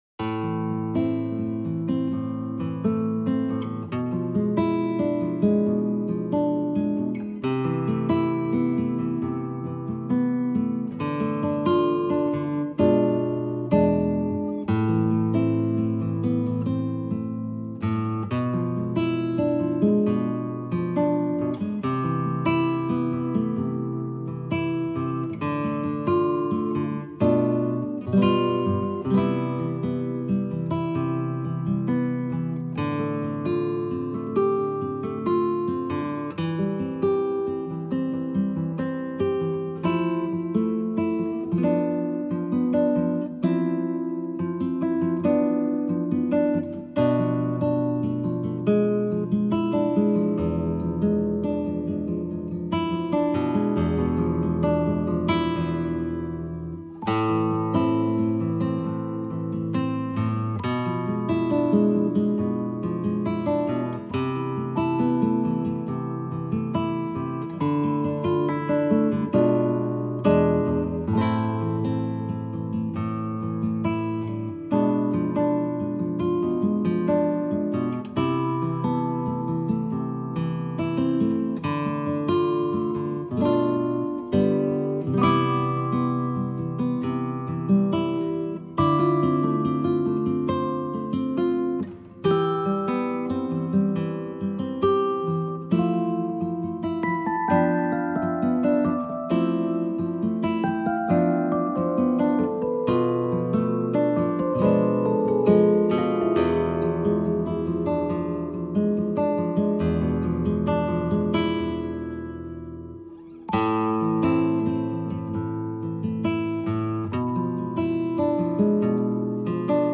guitar and bells